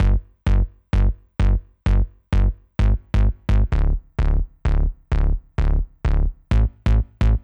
CLF Bass Riff G-Ab-A-D.wav